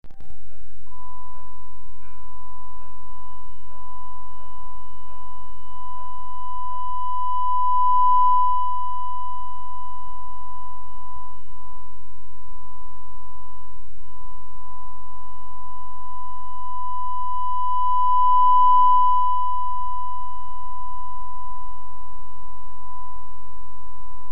I have a digital MEMS microphone connected to GPI1 on the TLV320AIC3262.
Example 1 = quiet 1khz reference tone with changing amplitude; the AGC does not amplify;